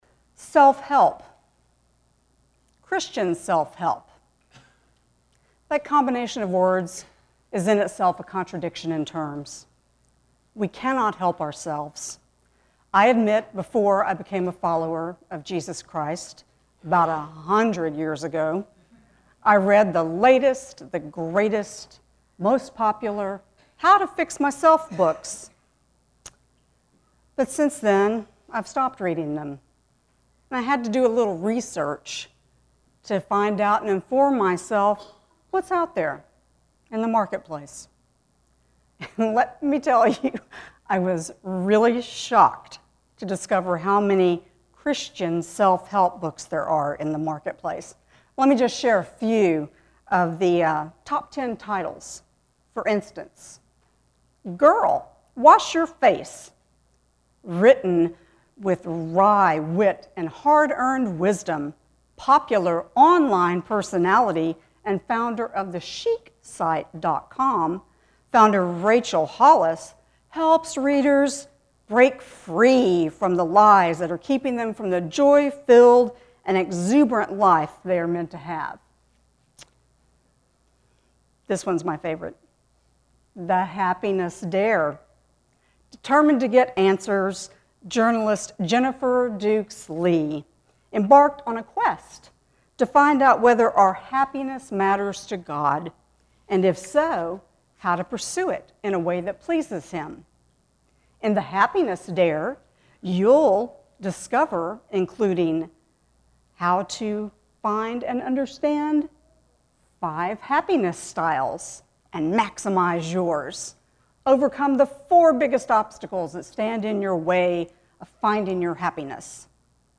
7-15-sermon.mp3